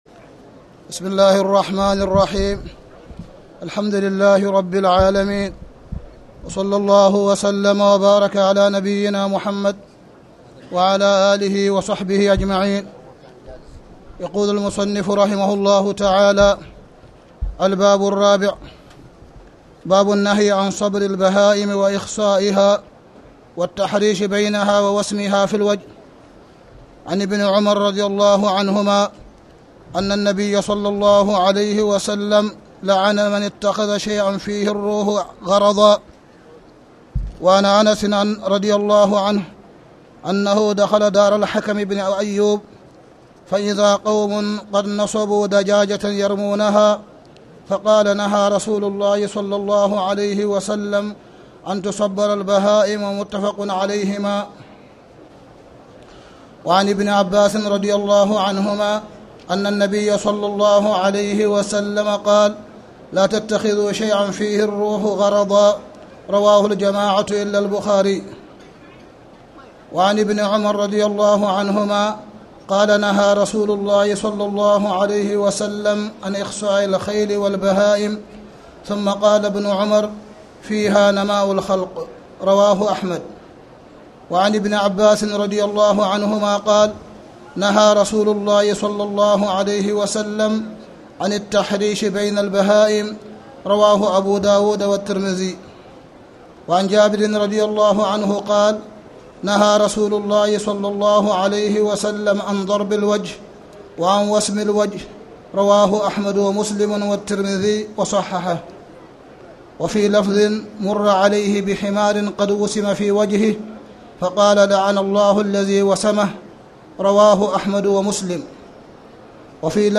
تاريخ النشر ٩ رمضان ١٤٣٨ هـ المكان: المسجد الحرام الشيخ: معالي الشيخ أ.د. صالح بن عبدالله بن حميد معالي الشيخ أ.د. صالح بن عبدالله بن حميد باب النهي عن صبر البهائم وإخصائها